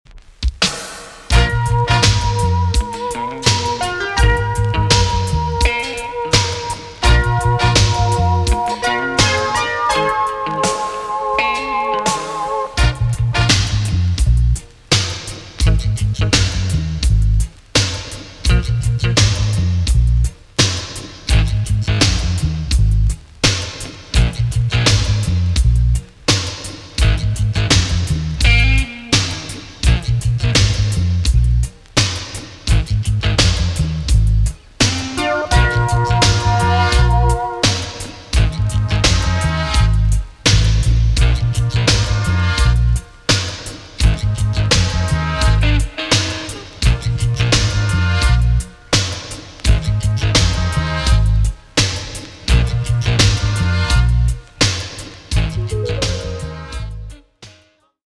TOP >80'S 90'S DANCEHALL
B.SIDE Version
EX- 音はキレイです。